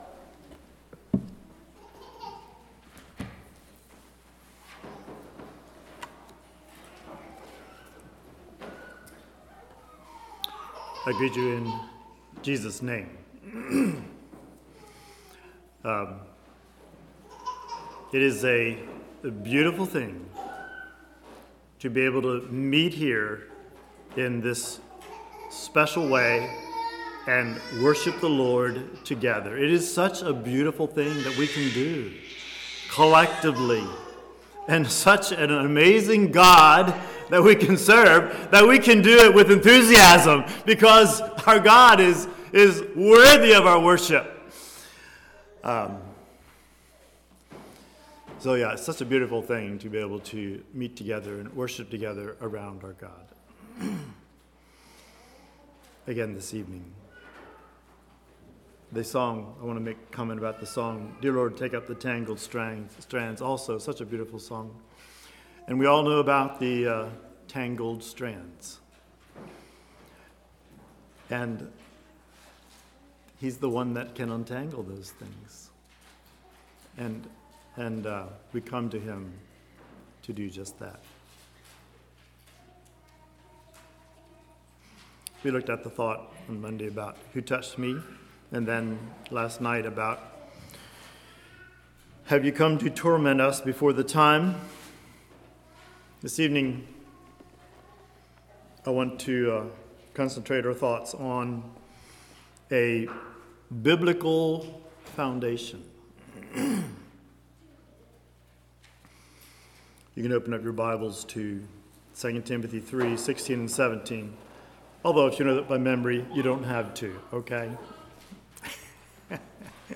Sermons
Calvary | All Day Meetings 2023